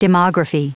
Prononciation du mot demography en anglais (fichier audio)